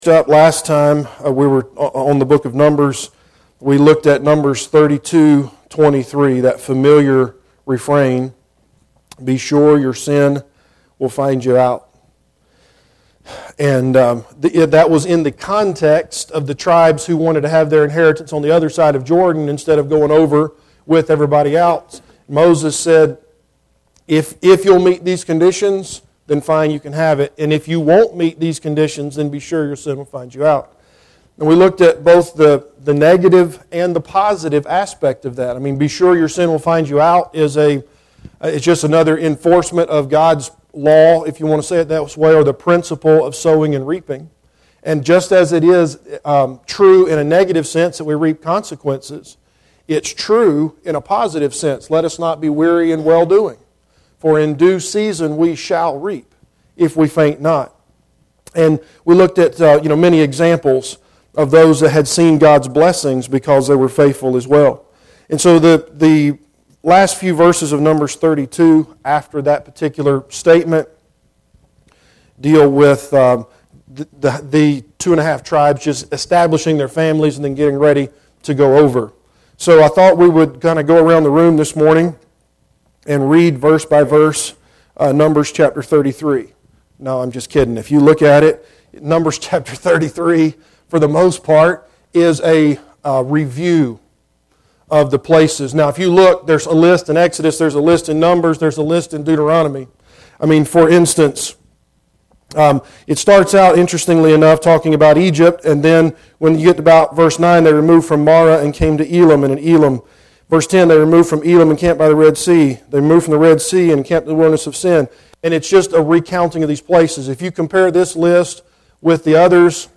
Passage: Numbers 23 Service Type: Adult Sunday School Class Bible Text